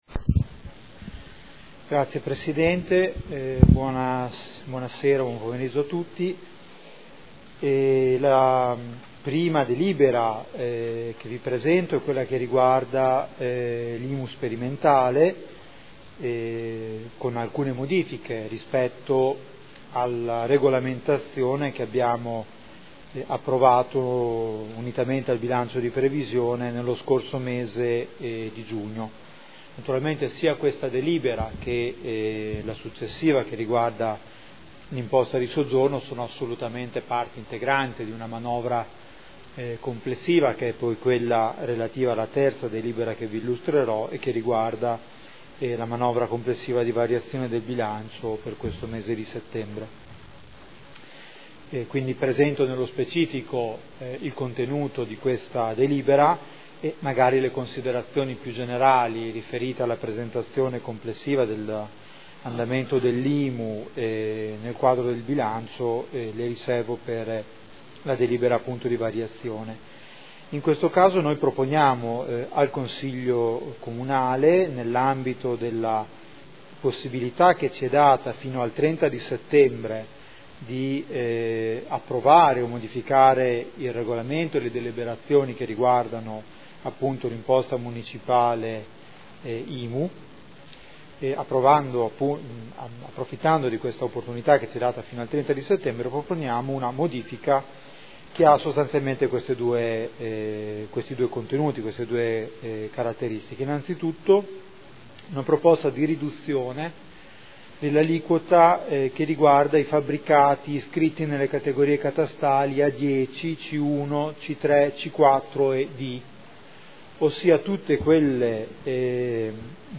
Giuseppe Boschini — Sito Audio Consiglio Comunale